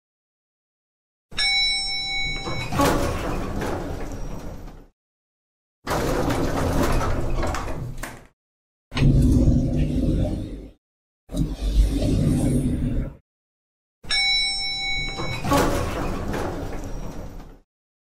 Aquí tendes un pequeno exemplo sonoro de como un son pode modificar a súa altura subindo ou baixando.
Son subindo
Son baixando
Ascensor__Efecto_de_sonido.mp3